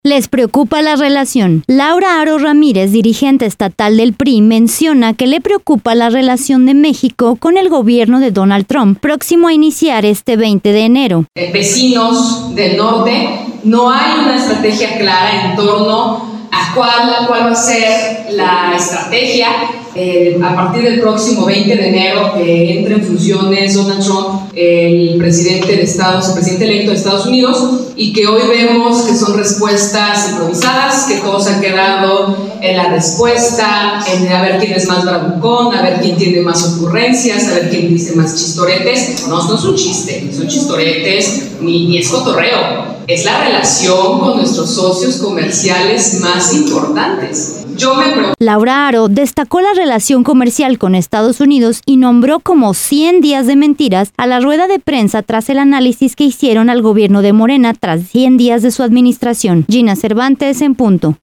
PRI Jalisco da rueda de prensa por “100 días de mentiras” de Claudia Sheinbaum